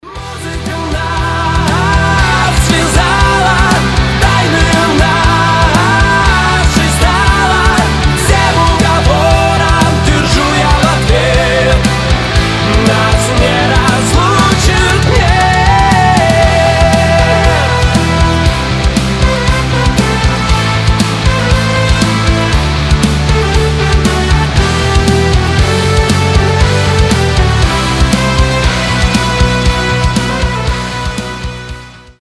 • Качество: 320, Stereo
громкие
Cover
Alternative Rock
Mashup